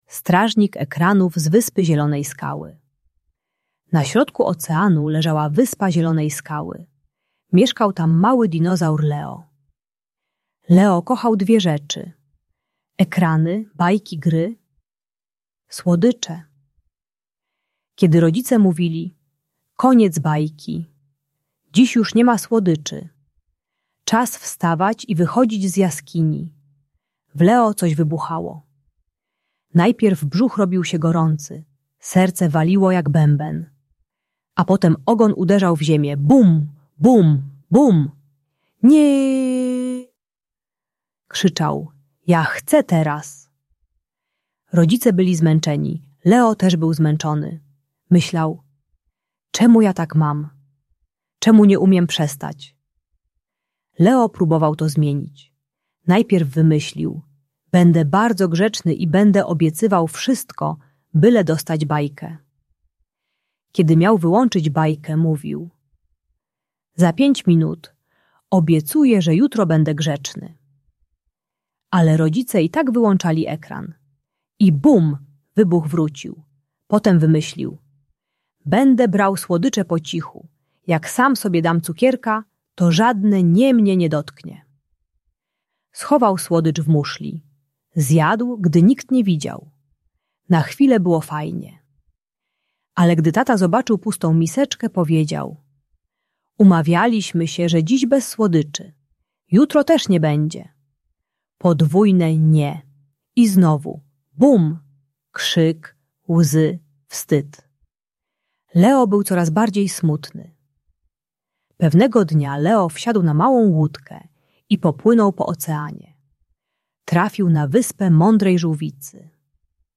Strażnik Ekranów z Wyspy Zielonej Skały - Bunt i wybuchy złości | Audiobajka